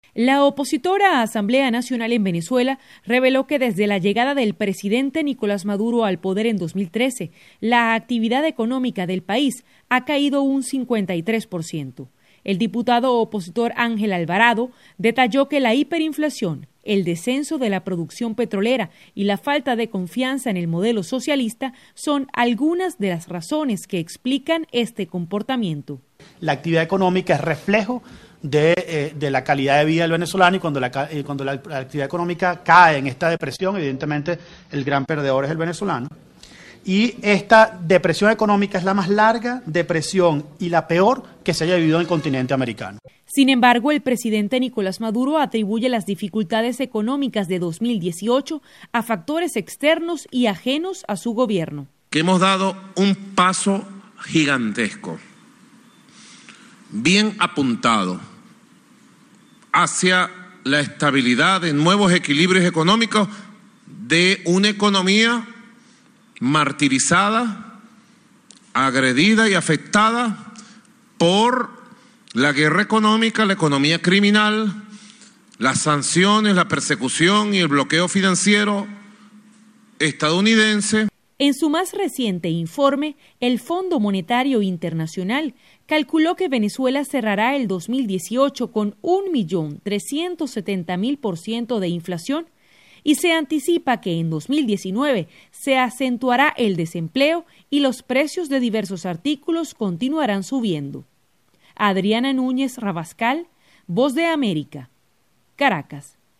VOA: Informe desde Venezuela